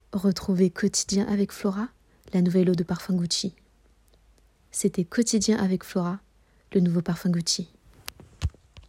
Voix-Off-FemmeFR-Billboard-TV